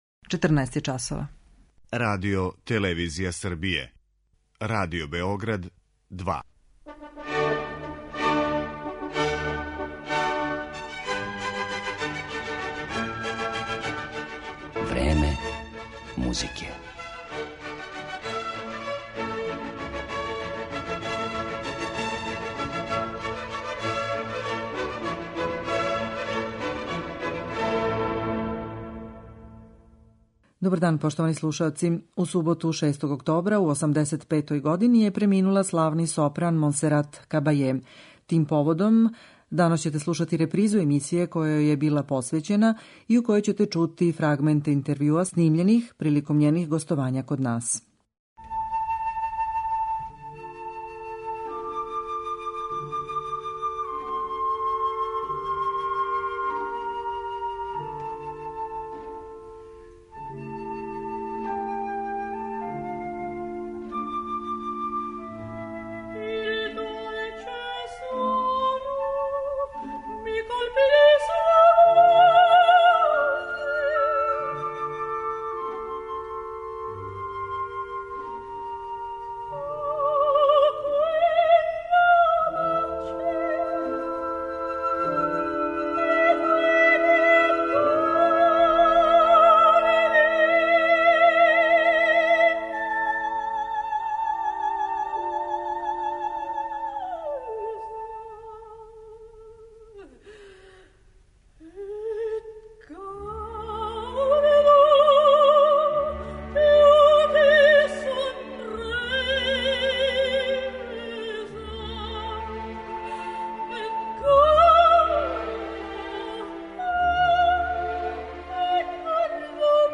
У суботу 6. октобра, у 85-ој години, преминула је једна од највећих вокалних солисткиња нашег доба, каталонски сопран Монсерат Кабаје. Тим поводом, данас ћете моћи да слушате репризу емисије која јој је посвећена и у којој ће бити емитовани одломци интервјуа снимљени са славном уметницом приликом њених гостовања код нас.